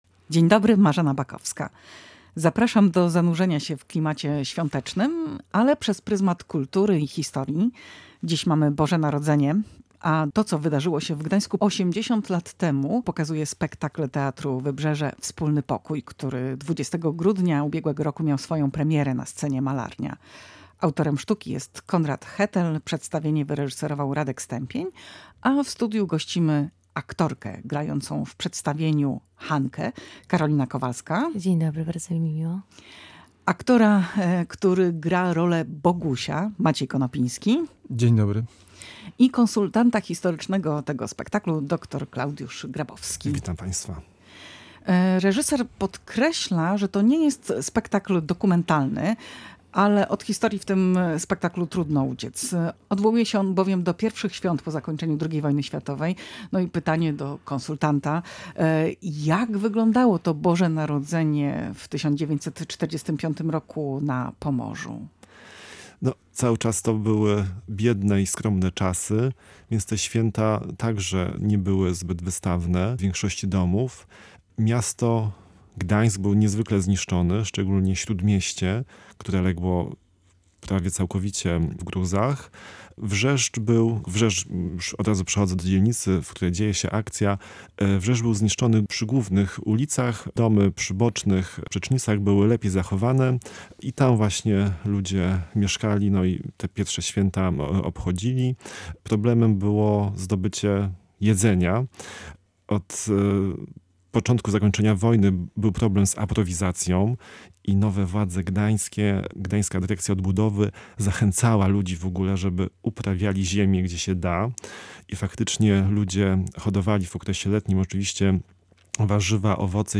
O spektaklu, ale także o faktach dotyczących powojennego Gdańska rozmawiali w studiu Radia Gdańsk